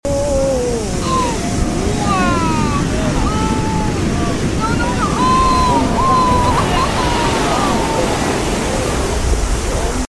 Tsunami Terror at Nazaré Cliffs